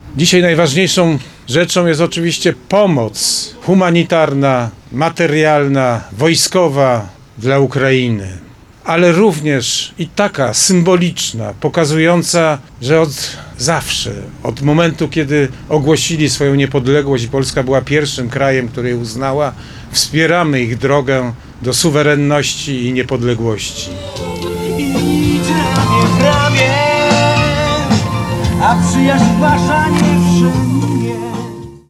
“Dzisiaj symbolem zniewolenia dalej jest armia już nie sowiecka, ale rosyjska na Ukrainie” – mówił Sonik podczas symbolicznego malowania betonowego cokołu.
Bogusław Sonik dla Radia Kraków: